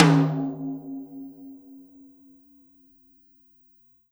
Tom Shard 06.wav